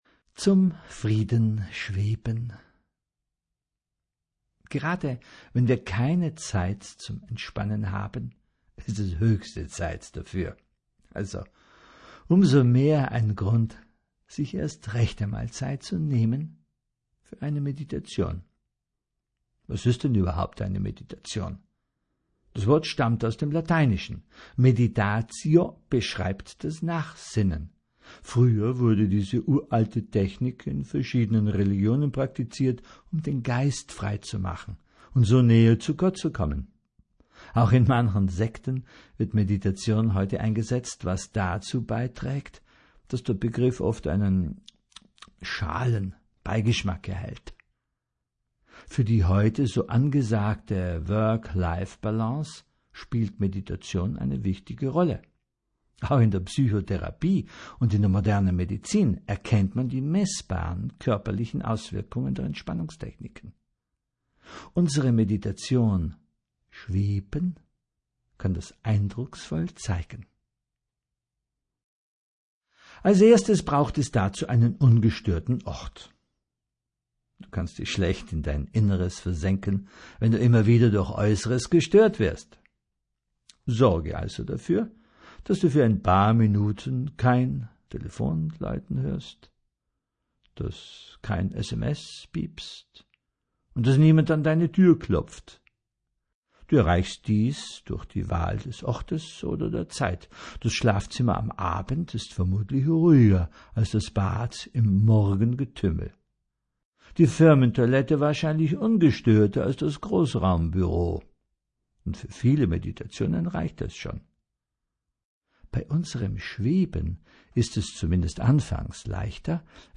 Das besondere Weihnachtsgeschenk für besondere Menschen: Meditationen zur Weihnachtszeit und zum Jahreswechsel.
Schenken Sie Entspannung und Einsichten mit Fantasiereisen, Stimmen, Geräuschen und Musik.